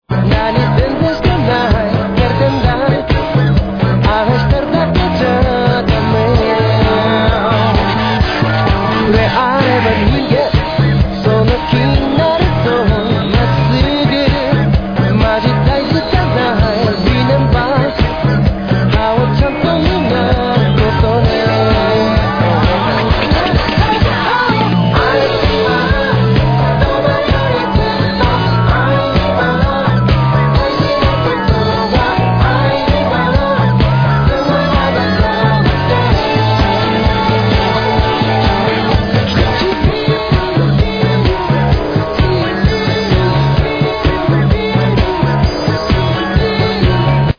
Tag       DANCE CLASSICS OTHER